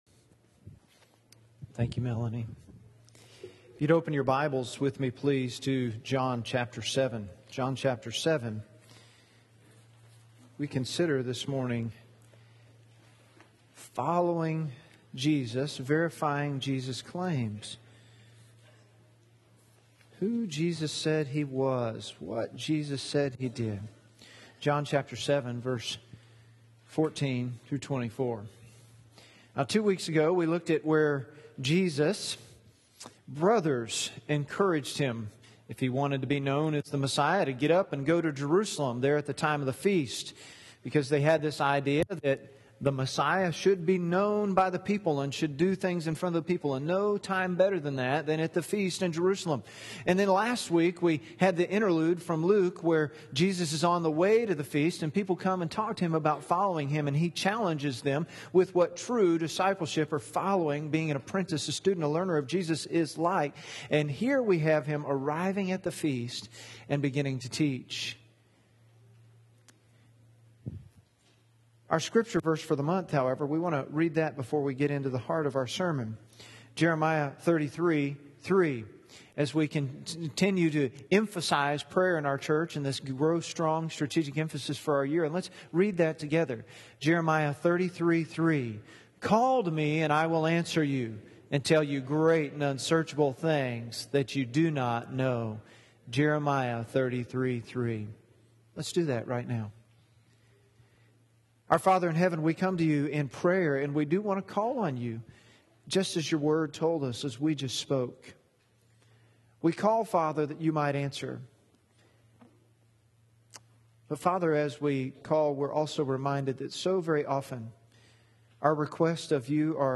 John 7:14-24 Sermon notes on YouVersion Following Jesus: Verifying Jesus' Claims